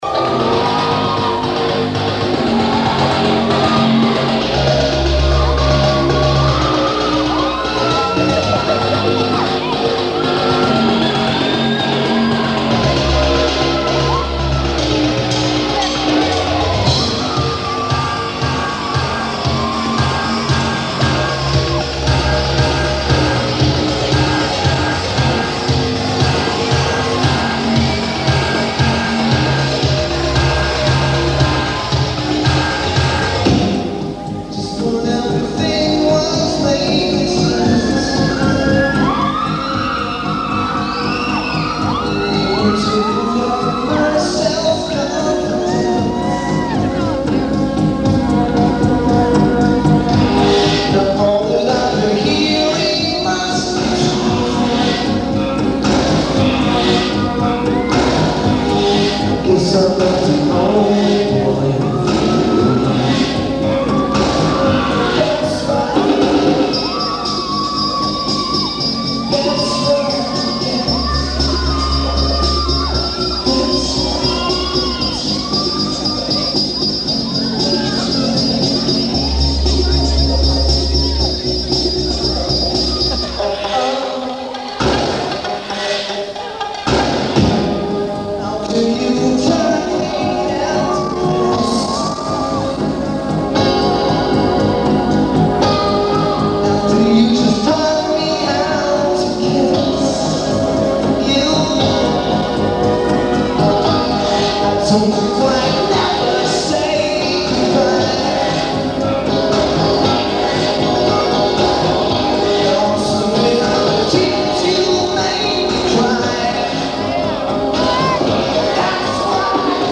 California Theatre
Very hissy, and muffled. This transfer is FAR superior.